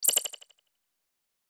Chirp Ui or Notification.wav